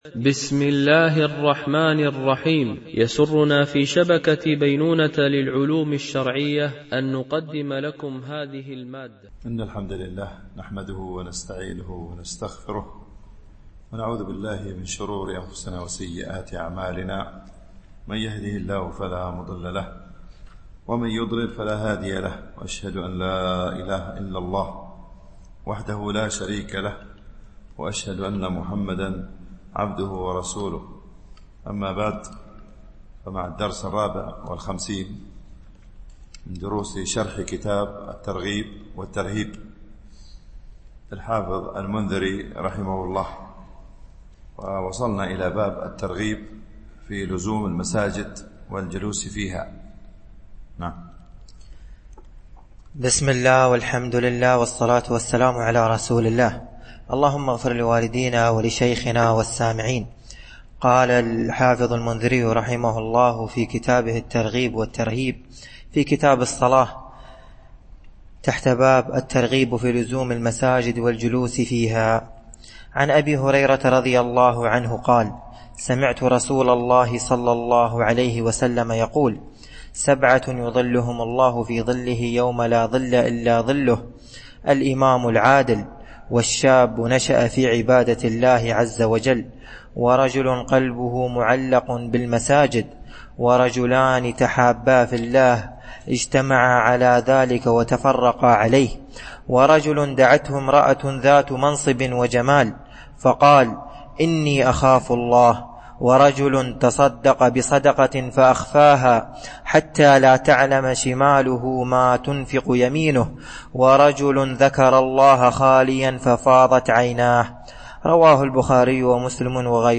شرح كتاب الترغيب والترهيب - الدرس 54 ( كتاب الصلاة .الحديث 494 - 502)